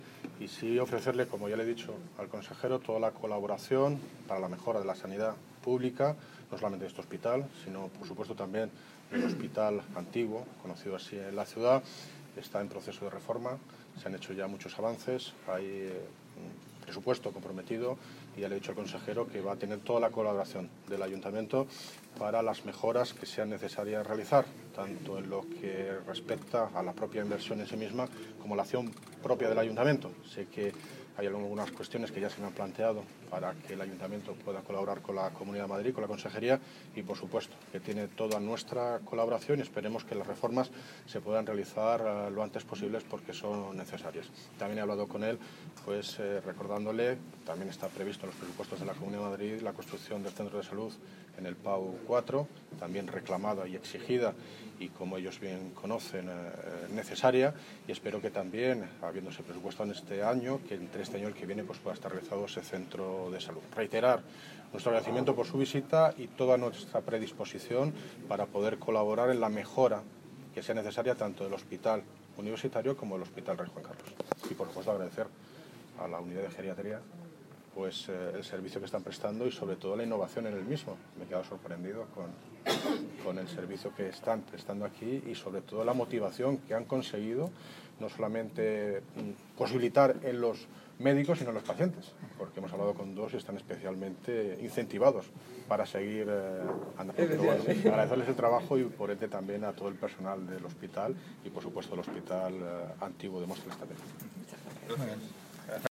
Audio - David Lucas (Alcalde de Móstoles) Sobre reforma del Hospital Universitario